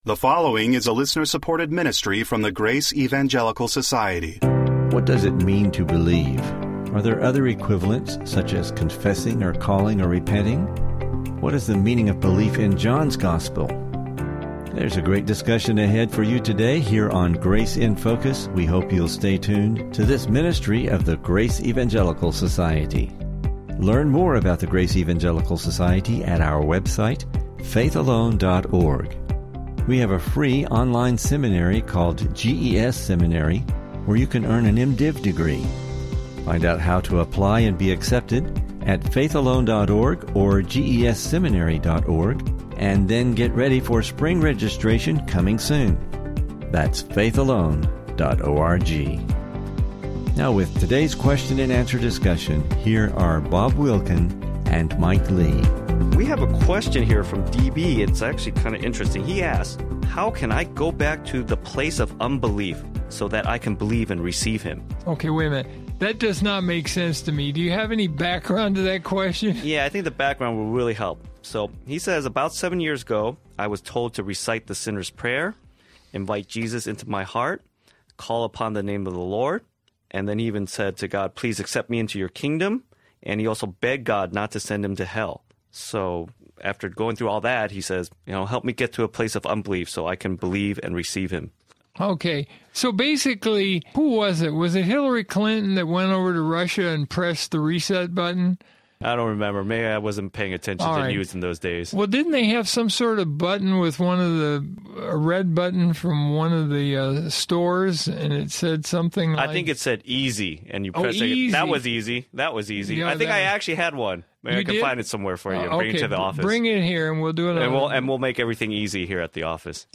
What does it mean to believe? Is praying, confessing, calling, repenting, behaving or baptizing equivalent to believing? What is the meaning of belief in John’s Gospel? Please listen for an interesting discussion and clarification on this topic.